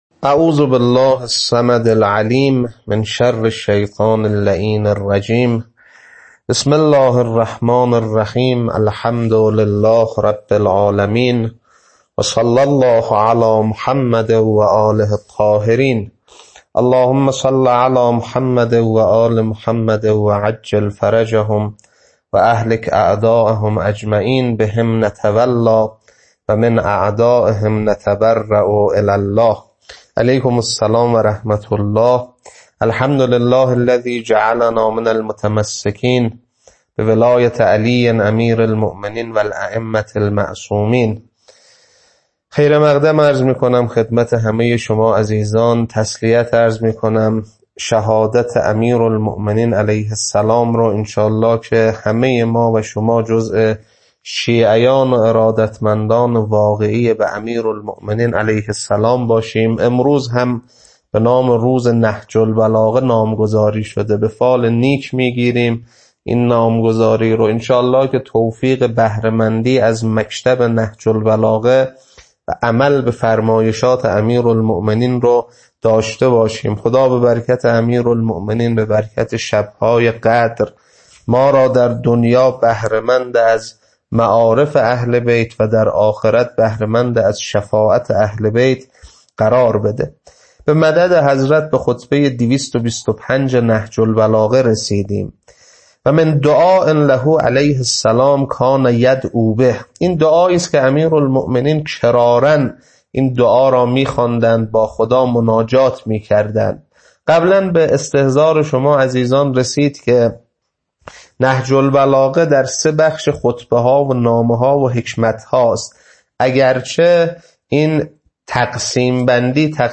خطبه 225.mp3